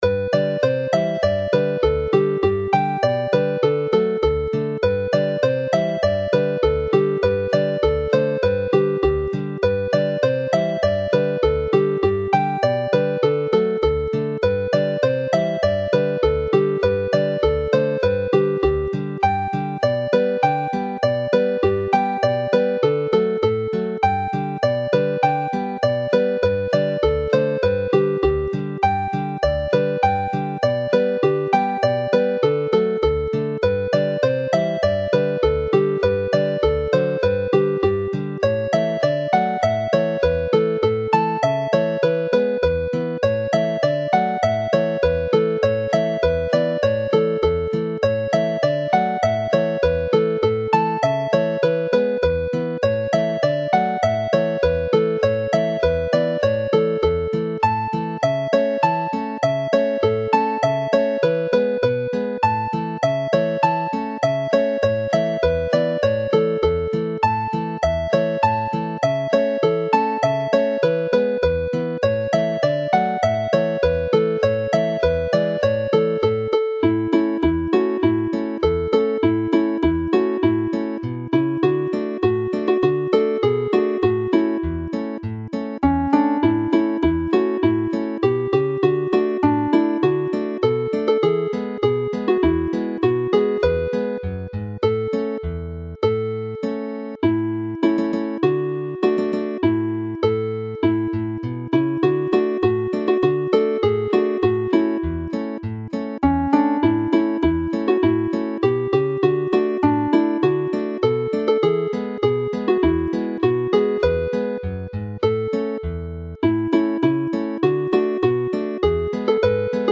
The set is played fast and lively.